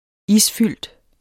Udtale [ -ˌfylˀd ]